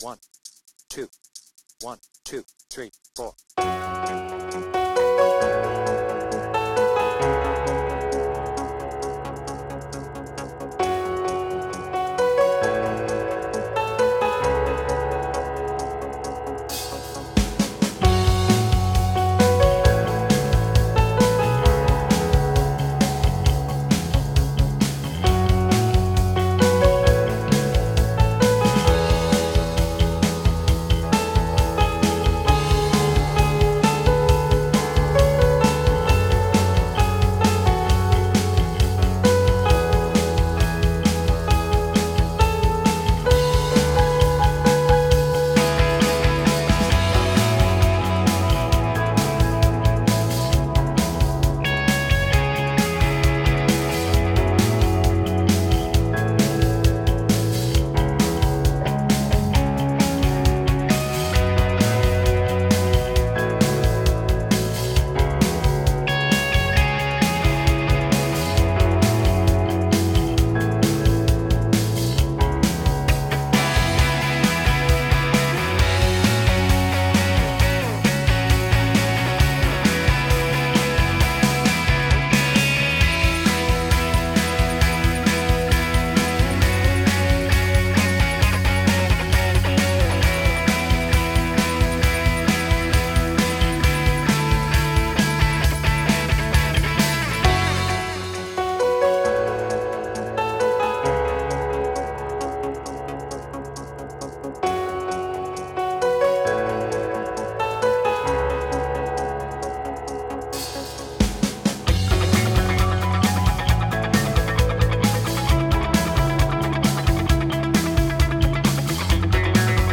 BPM : 133
Tuning : D